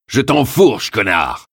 Category: Games   Right: Personal
Tags: Duke Nukem sounds quotes Dukenukem Ultimate Ultime francais france repliques sons bruits voix phrases